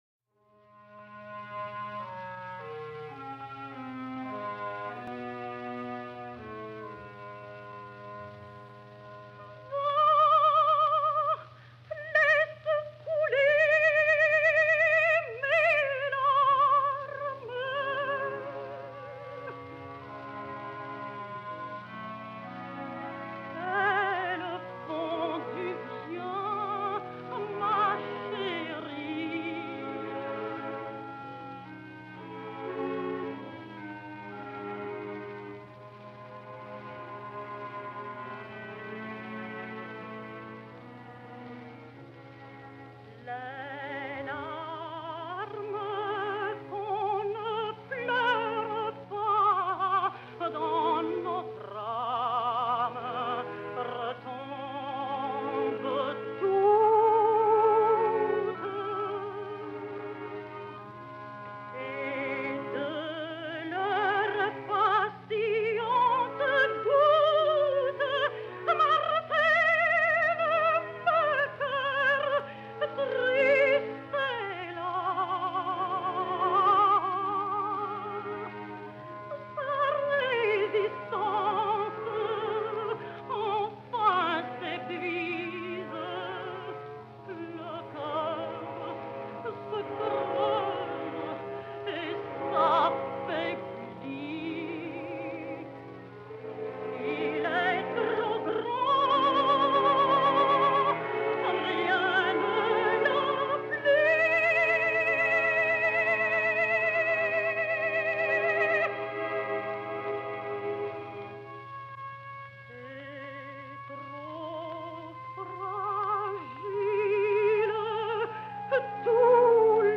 A l’ària de les llàgrimes, la línia melòdica és ondulant i descendent per mostrar el plor de la protagonista.